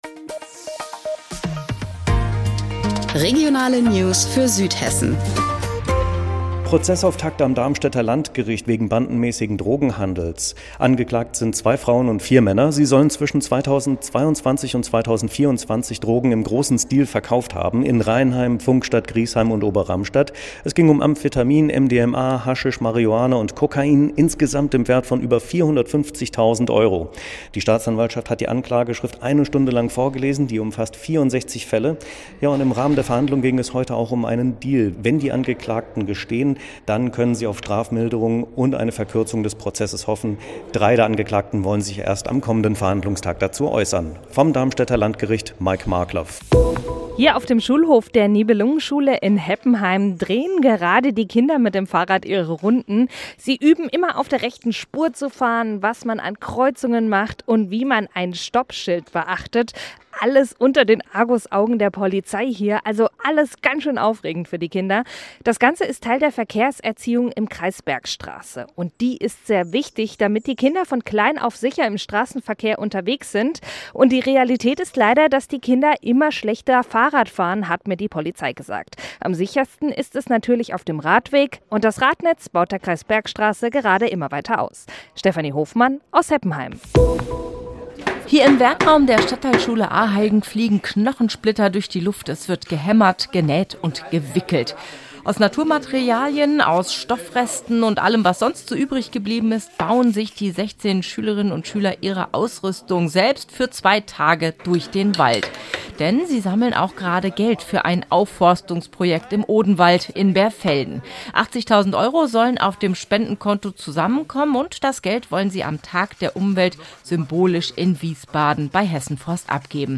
Mittags eine aktuelle Reportage des Studios Darmstadt für die Region